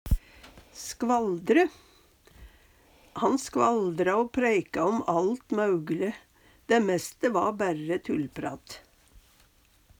skvaldre - Numedalsmål (en-US)